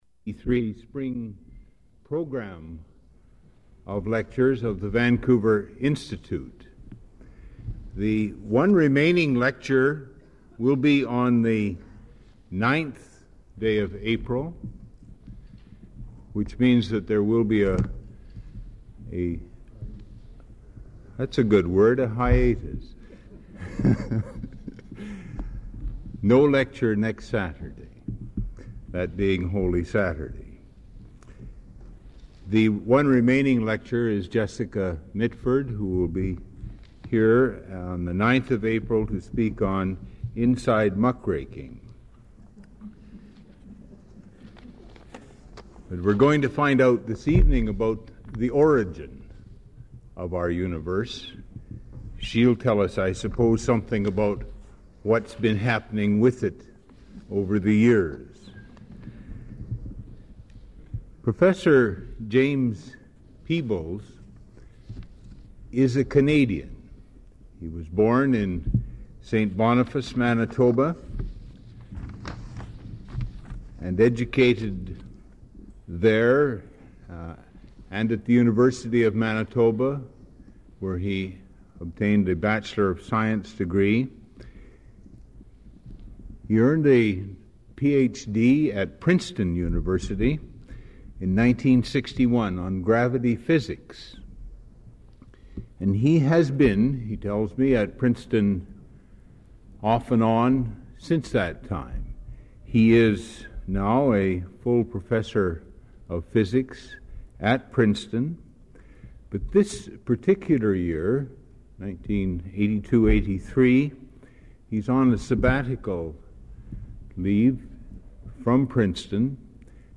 Item consists of a digitized copy of an audio recording of a Vancouver Institute lecture given by James Peebles on March 26, 1983.